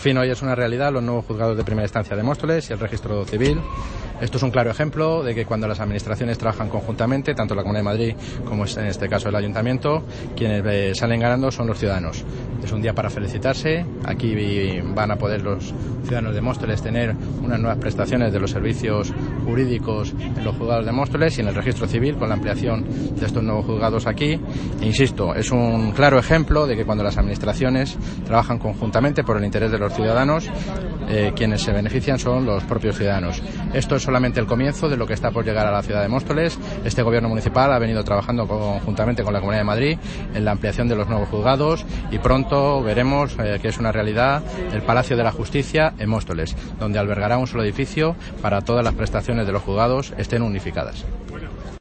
Audio - Roberto Sánchez (Concejal de Presidencia, Urbanismo, Seguridad y comunicación)